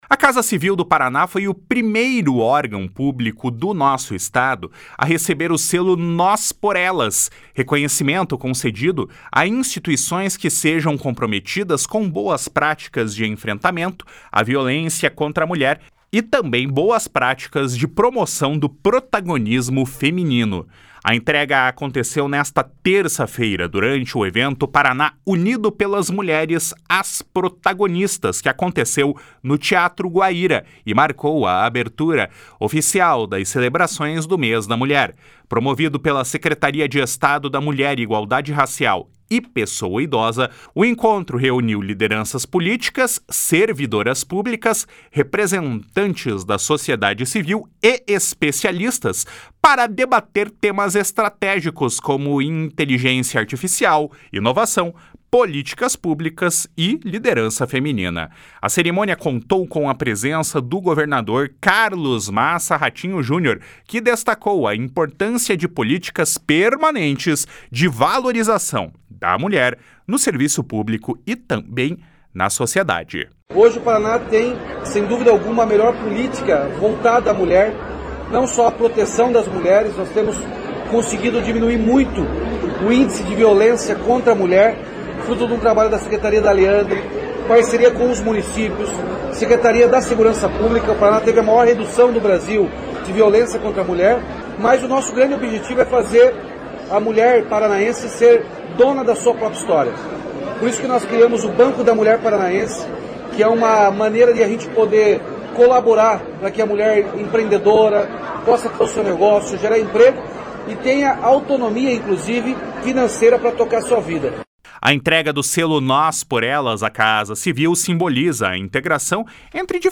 A Casa Civil do Paraná foi o primeiro órgão público do Estado a receber o selo “Nós Por Elas”, reconhecimento concedido a instituições comprometidas com boas práticas de enfrentamento à violência contra a mulher e promoção do protagonismo feminino. A entrega ocorreu nesta terça-feira, durante o evento “Paraná Unido Pelas Mulheres – As Protagonistas”, no Teatro Guaíra, que marcou a abertura oficial das celebrações do Mês da Mulher.
// SONORA RATINHO JUNIOR //